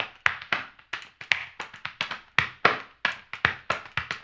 HAMBONE 11-R.wav